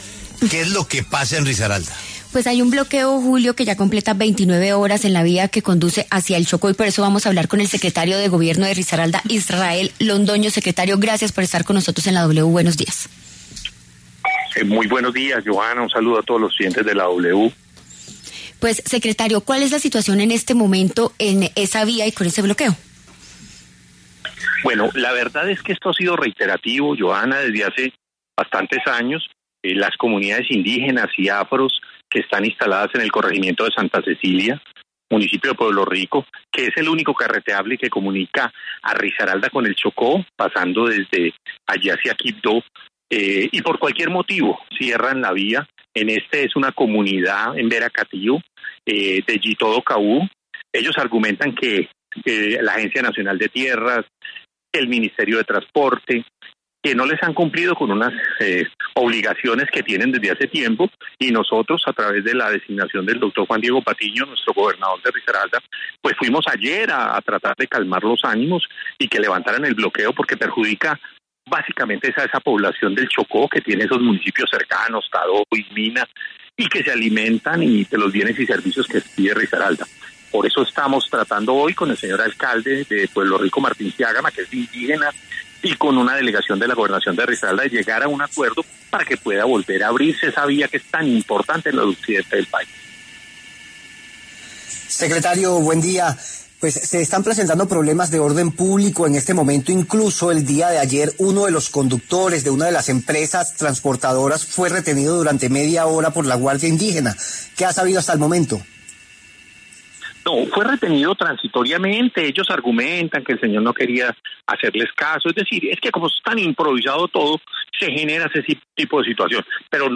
Los manifestantes exigen la presencia de autoridades nacionales para atender demandas sobre derechos territoriales y ancestrales. El secretario de Gobierno de la región, Israel Londoño, conversó sobre el tema en La W.